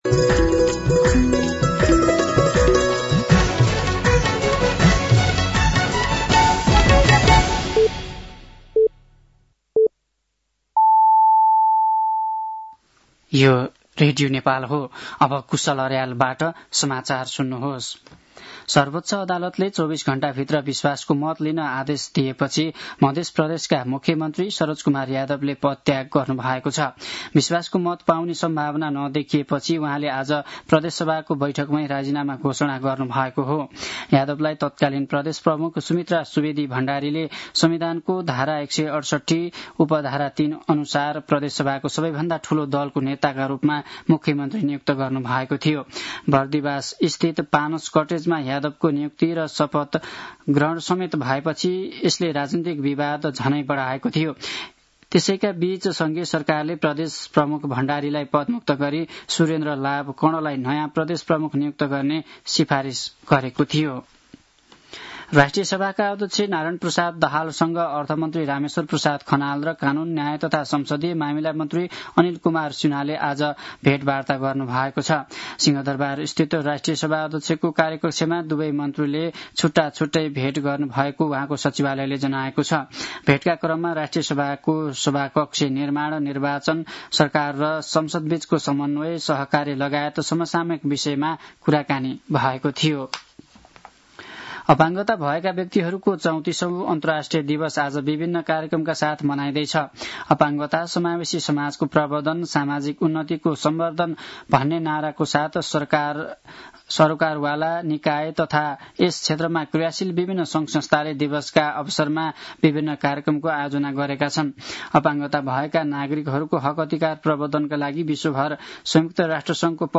साँझ ५ बजेको नेपाली समाचार : १७ मंसिर , २०८२